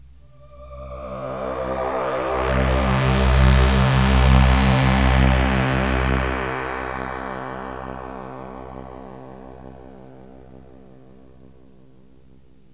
AMBIENT ATMOSPHERES-2 0003.wav